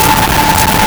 Player_Glitch [29].wav